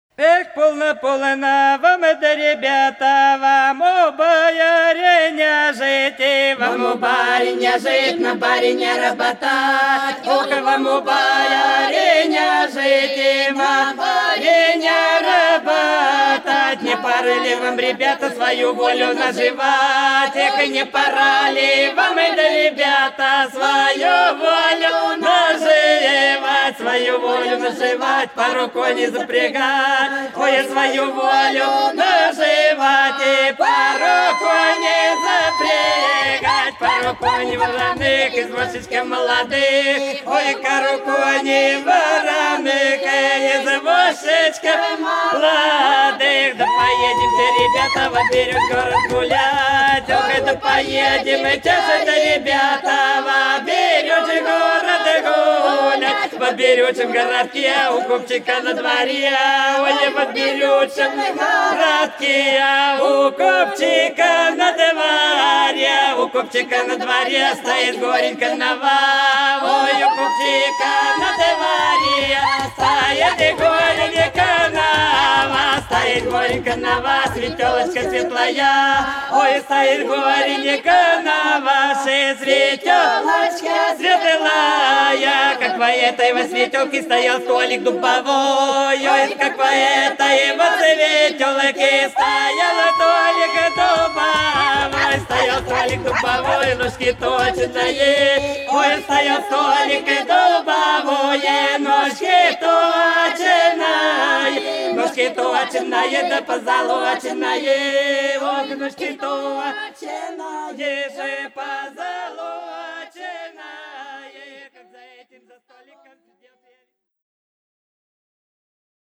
Хороша наша деревня Полно, полно вам, ребята у барина жить - плясовая (с. Афанасьевка)
14_Полно,_полно_вам,_ребята_у_барина_жить_-_плясовая.mp3